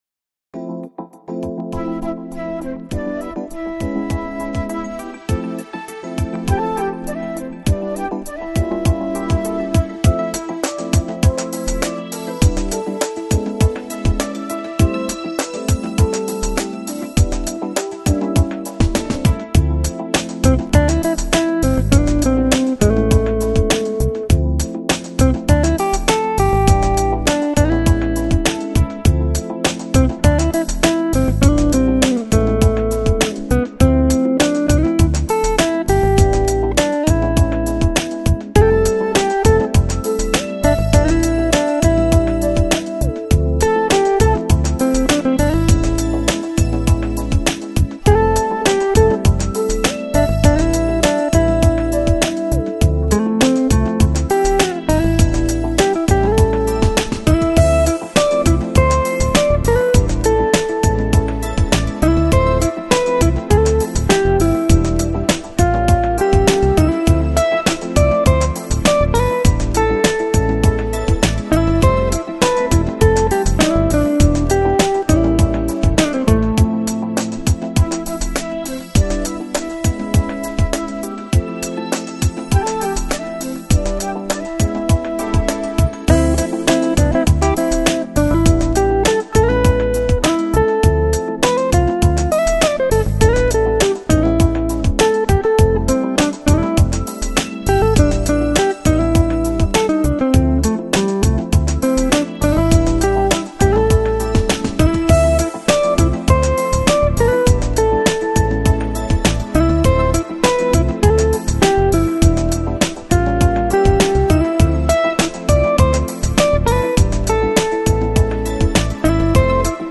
Жанр: Lounge, Chill Out, Smooth Jazz, Easy Listening